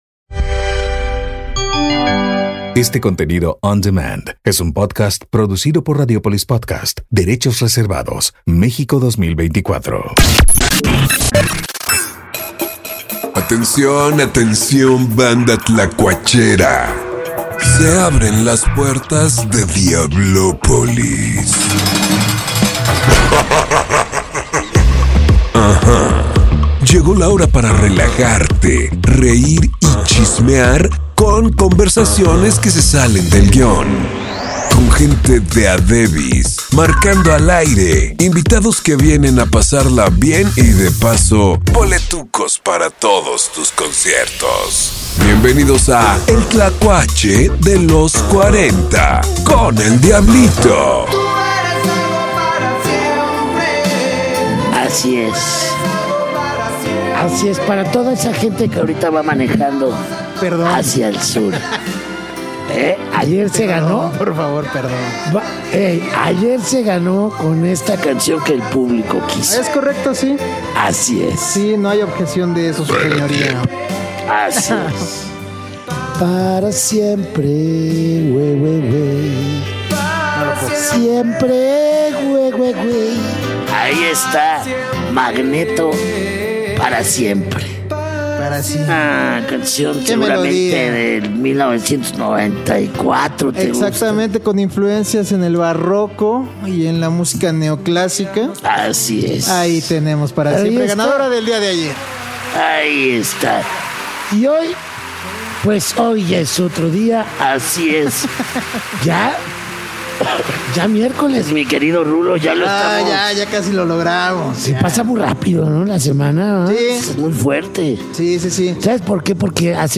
Arath de la Torre en vivo desde Diablopolis